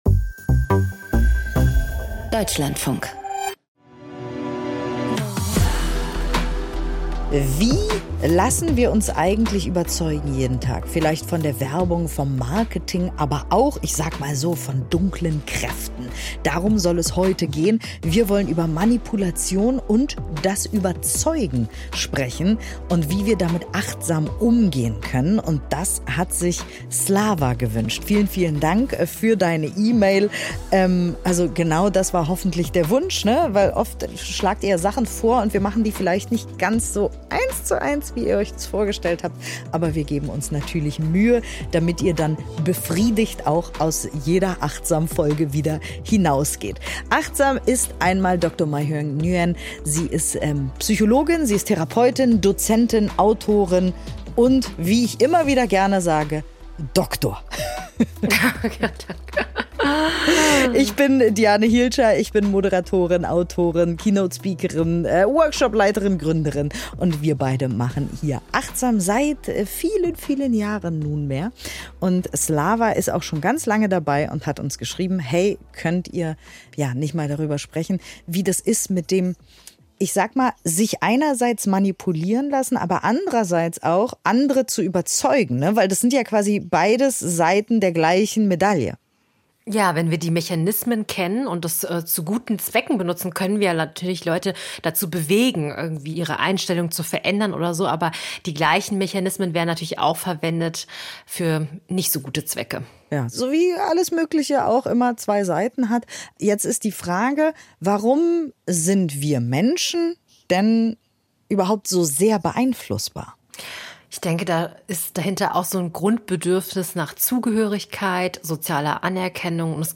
Geleitete Meditation zur Emotionsregulation